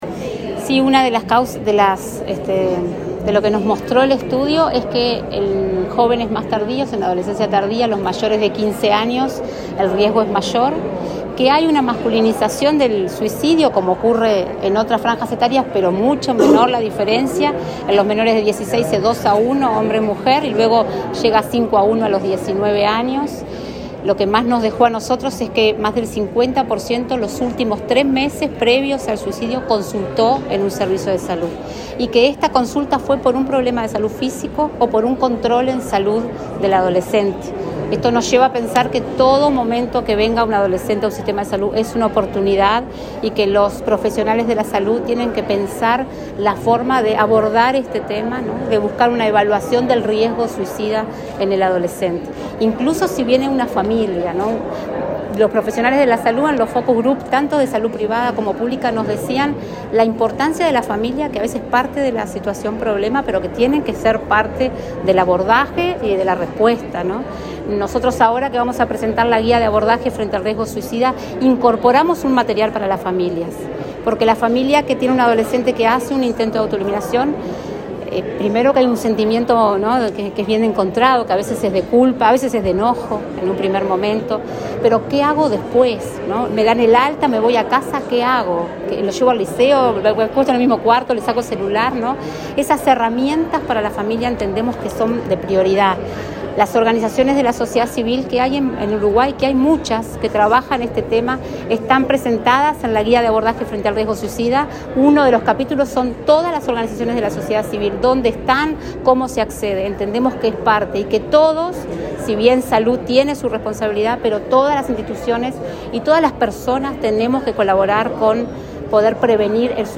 Declaraciones de la directora del programa Adolescencia y Juventud del MSP, Lorena Quintana
Declaraciones de la directora del programa Adolescencia y Juventud del MSP, Lorena Quintana 10/10/2023 Compartir Facebook X Copiar enlace WhatsApp LinkedIn La directora del programa Adolescencia y Juventud del Ministerio de Salud Pública (MSP), Lorena Quintana, dialogó con la prensa sobre la presentación, este martes 10 en esa cartera, de un manual de prevención del suicidio en adolescentes.